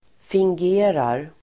Ladda ner uttalet
Uttal: [fingg'e:rar]